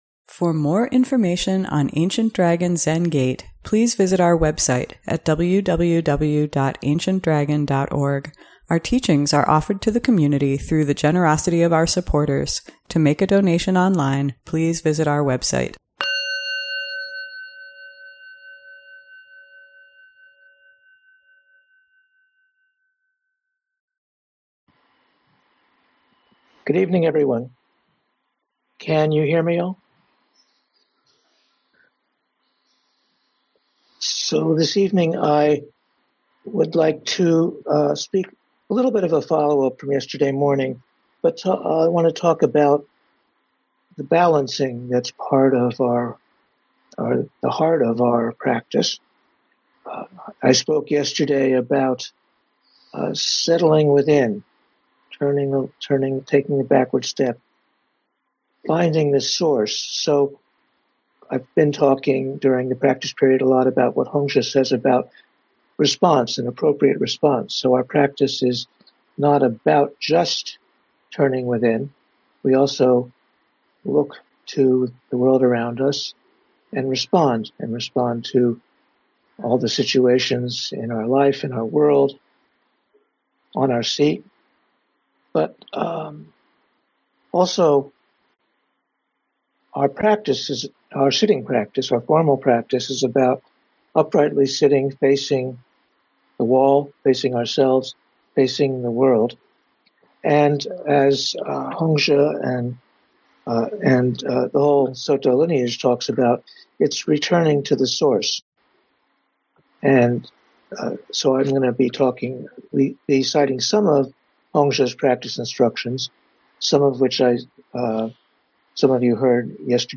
ADZG Monday Night Dharma Talk